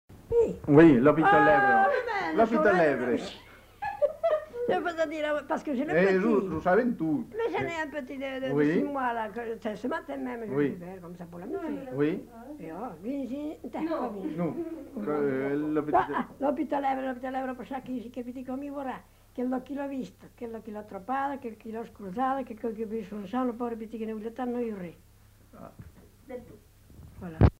Aire culturelle : Périgord
Lieu : Cendrieux
Type de voix : voix de femme
Production du son : récité
Classification : formulette enfantine